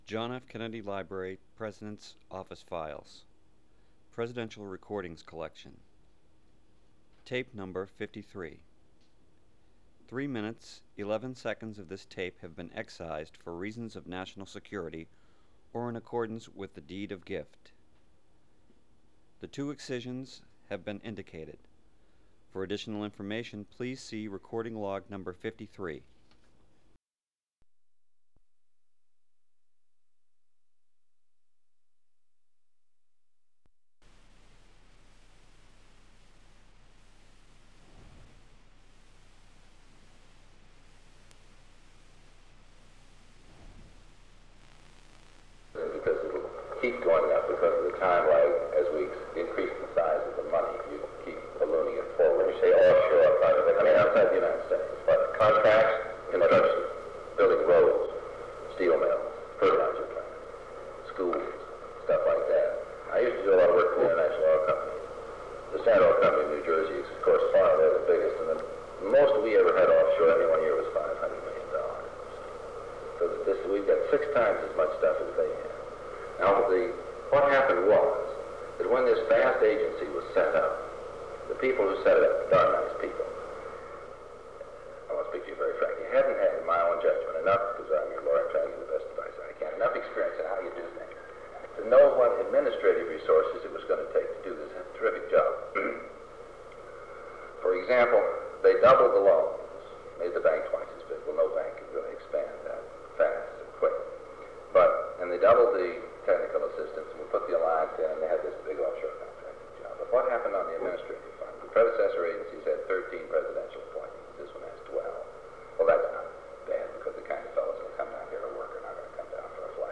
Secret White House Tapes | John F. Kennedy Presidency Meeting on Foreign Aid Rewind 10 seconds Play/Pause Fast-forward 10 seconds 0:00 Download audio Previous Meetings: Tape 121/A57.